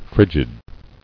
[frig·id]